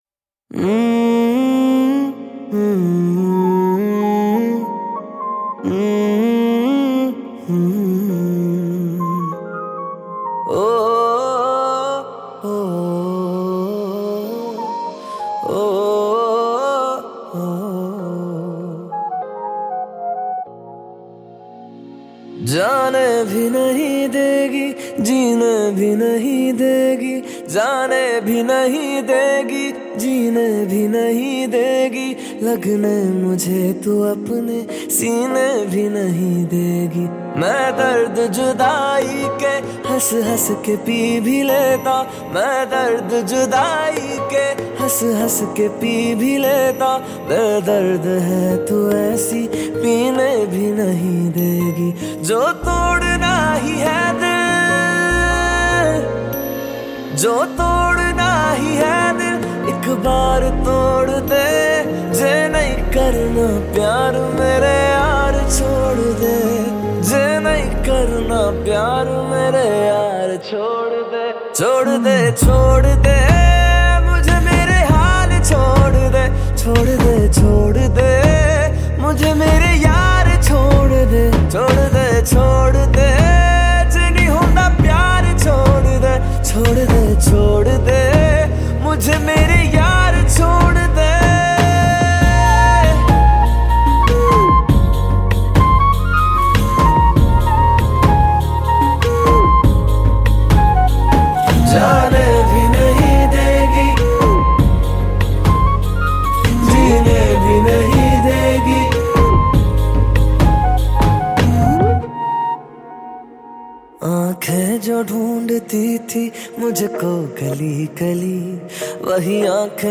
Indian POP Mp3 Song